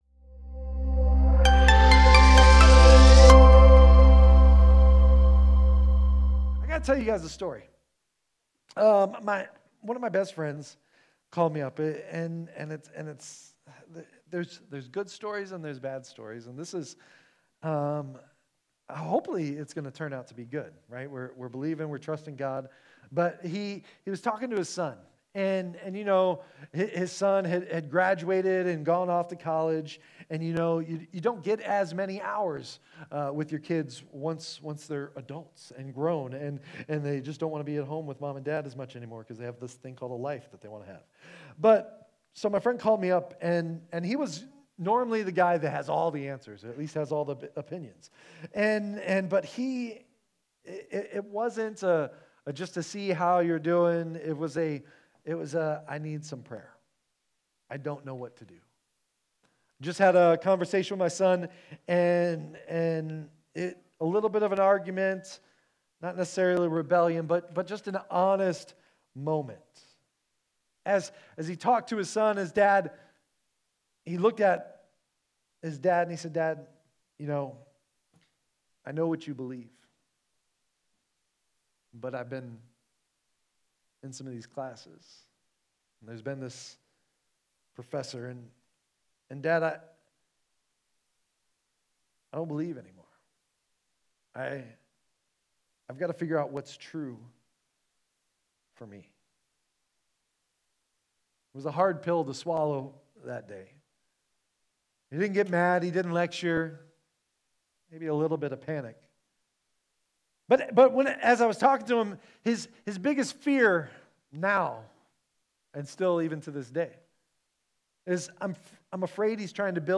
Sermons | Innovation Church